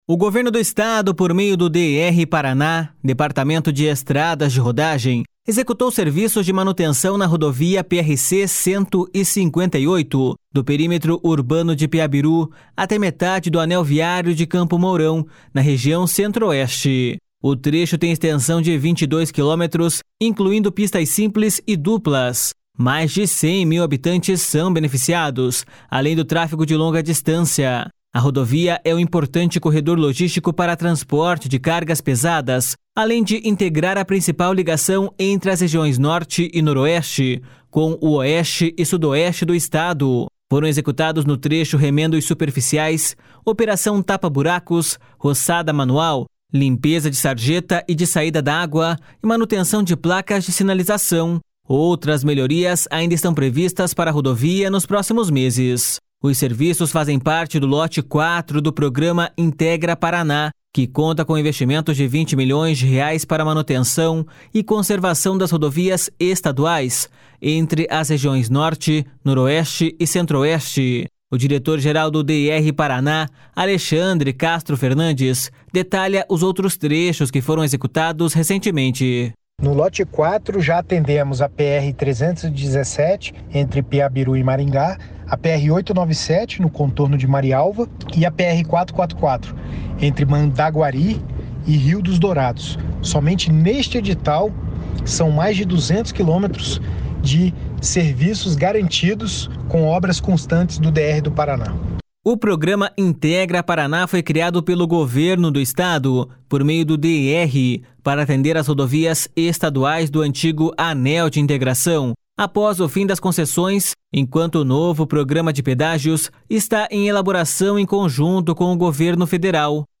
O diretor-geral do DER/PR, Alexandre Castro Fernandes, detalha os outros trechos que foram executados recentemente.// SONORA ALEXANDRE CASTRO FERNANDES.//